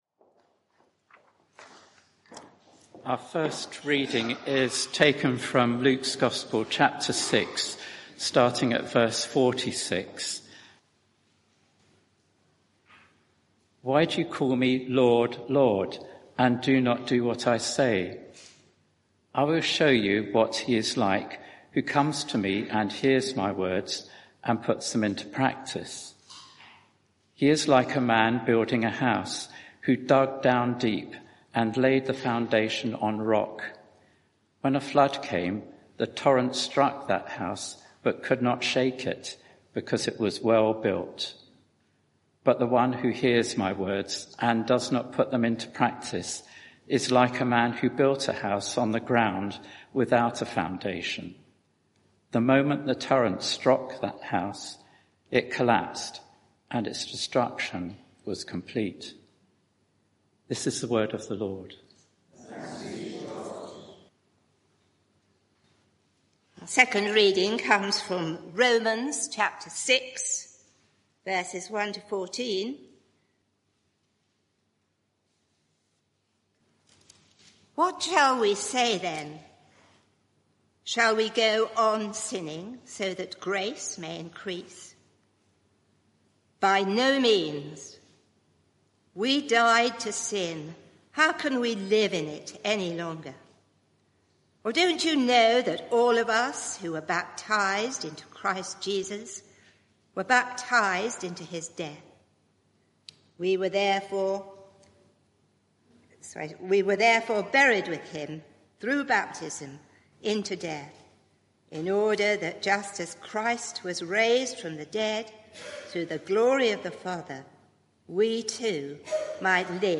Media for 11am Service on Sun 19th Nov 2023 11:00 Speaker
Sermon (audio) Search the media library There are recordings here going back several years.